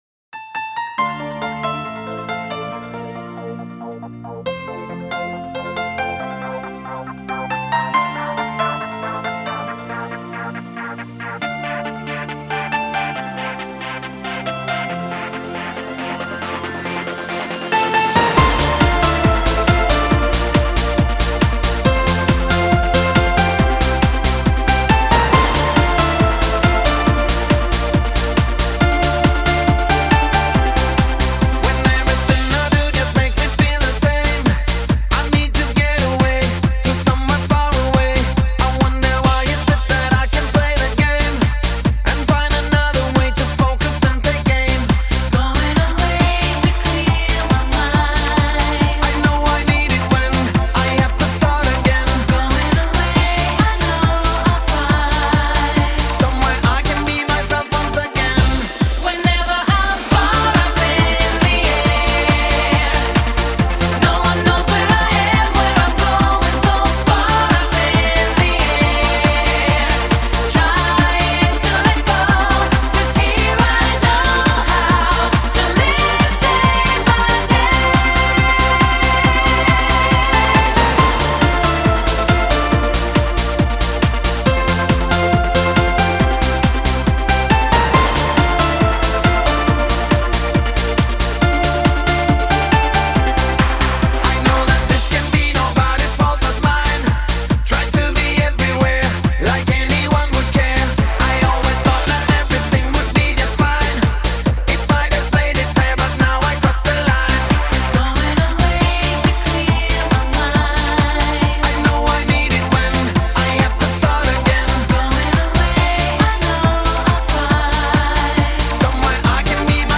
Жанр: Eurodance, Pop